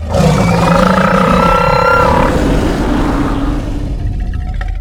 sonic scream.ogg